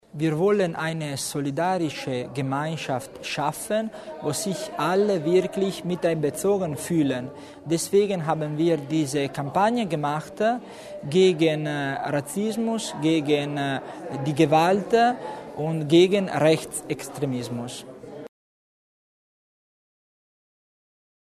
Landeshauptmannstellvertreter Tommasini erklärt die Ziele des Projektes